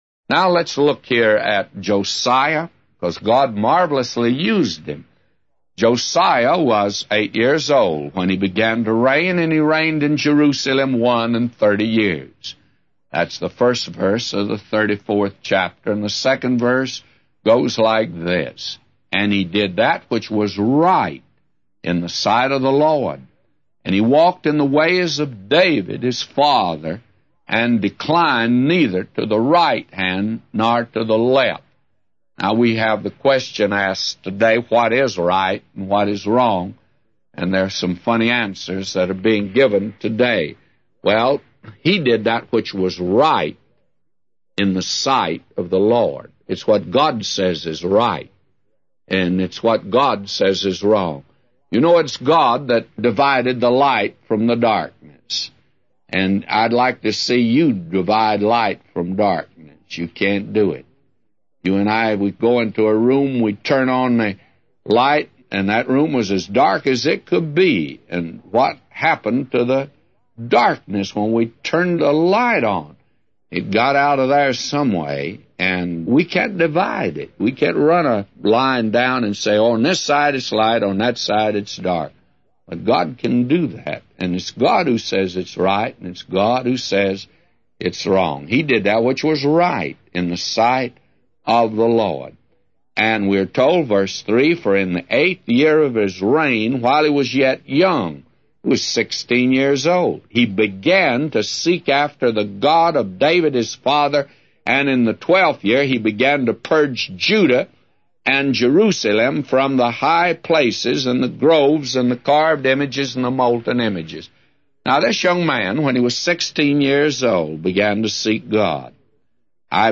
A Commentary By J Vernon MCgee For 2 Chronicles 34:1-999